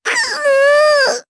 Miruru-Vox_Damage_jp_01.wav